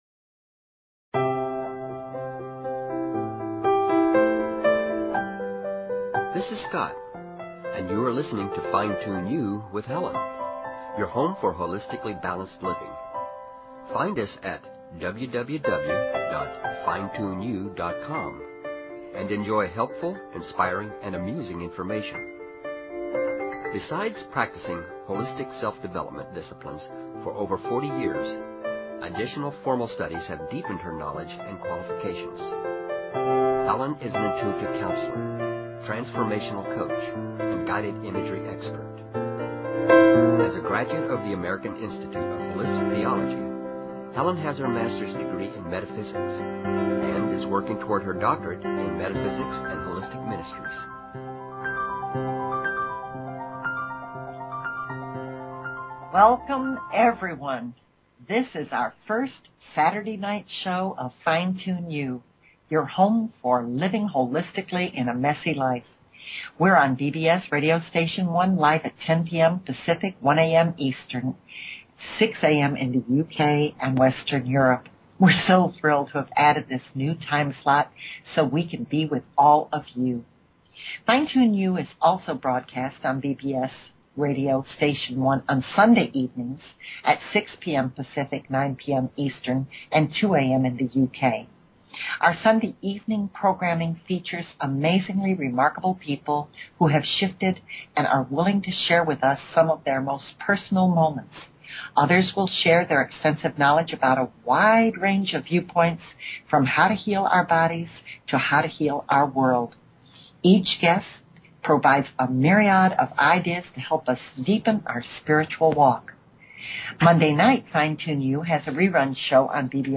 Talk Show Episode, Audio Podcast, Fine_Tune_You and Courtesy of BBS Radio on , show guests , about , categorized as